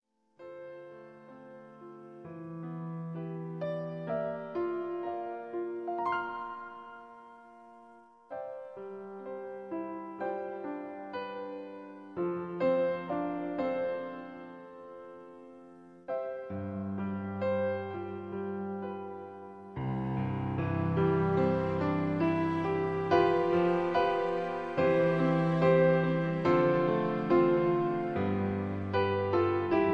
(Key-C) Karaoke MP3 Backing Tracks